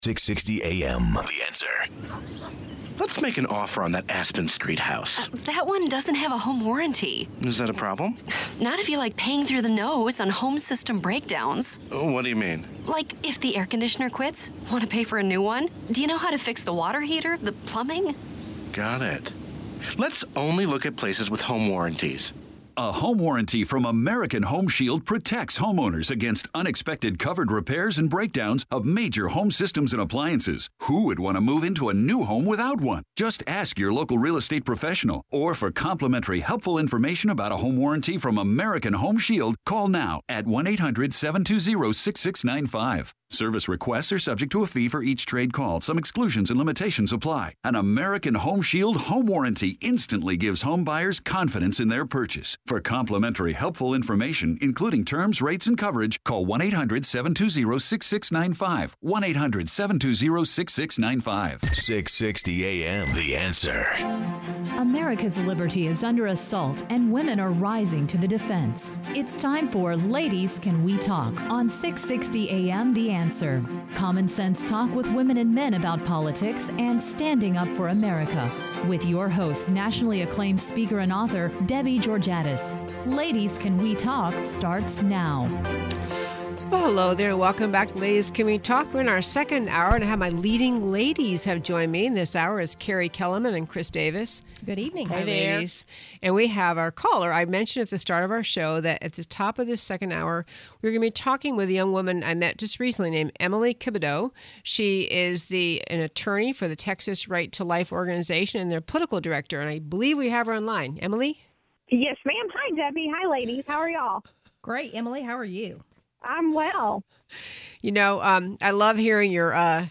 Listen to the podcast from the second hour of our September 6th show on 660AM.
Radio Show Podcasts